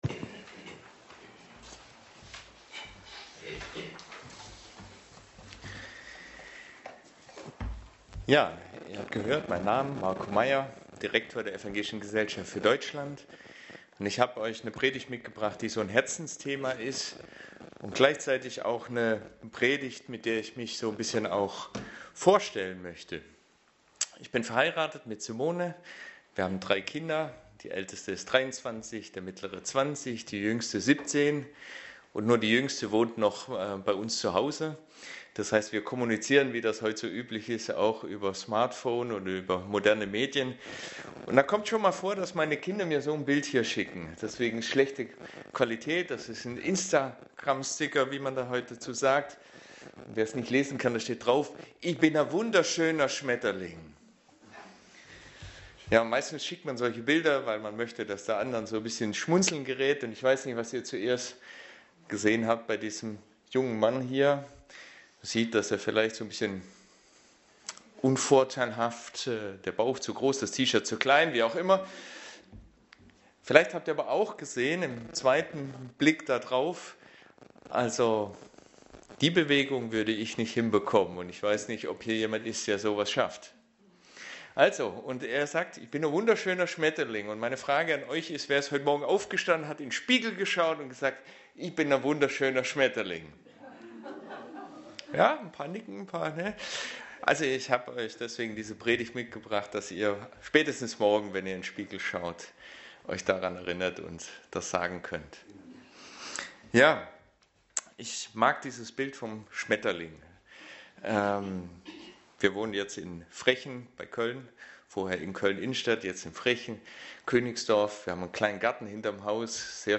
Sonstige Passage: Lukas 19,1-10 Dienstart: Predigt Themen